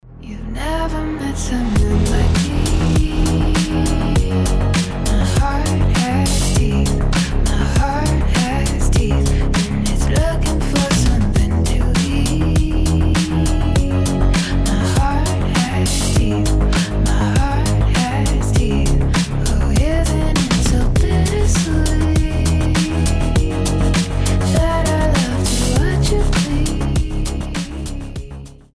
спокойные женский голос